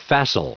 Prononciation du mot facile en anglais (fichier audio)
Prononciation du mot : facile